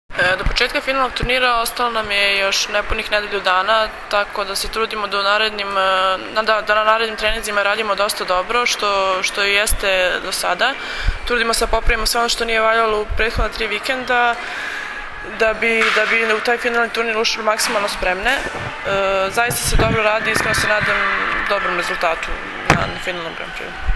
IZJAVA MILENE RAŠIĆ, BLOKERA SRBIJE